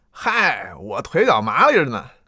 序章与第一章配音资产
c01_9车夫_4.wav